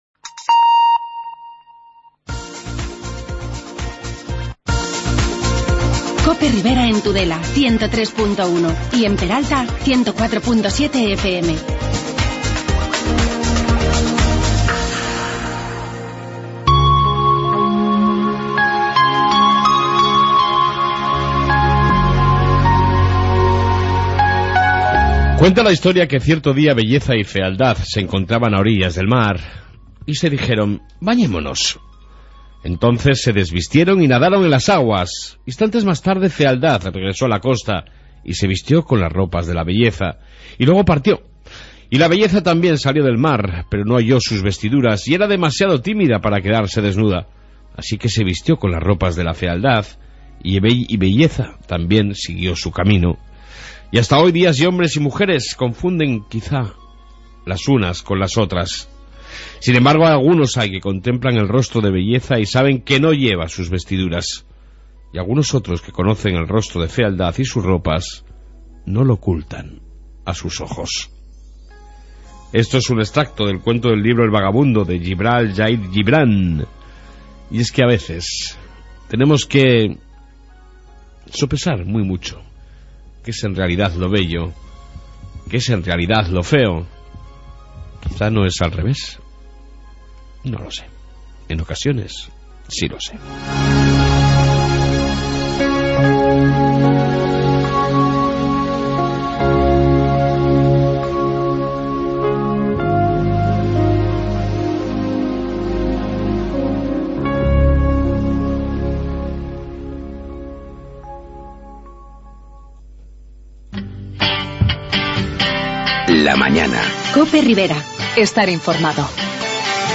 AUDIO: Refleción Matutina y amplia entrevista con el alcalde Eneko Larrarte y el concejal de Hacienda Jose Ängel Andrés